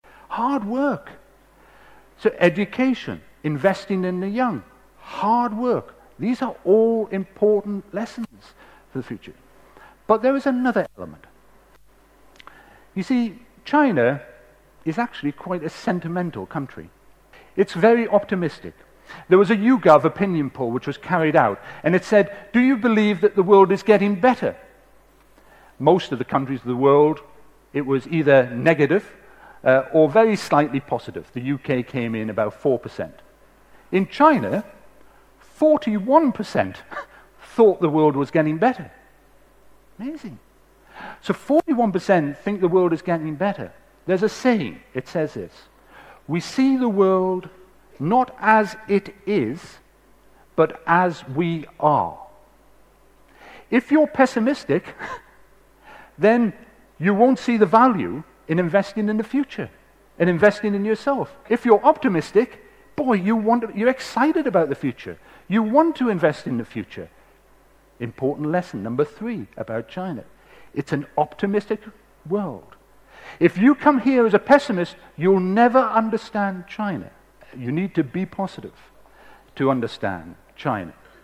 TED演讲:我难忘的一次中国行(8) 听力文件下载—在线英语听力室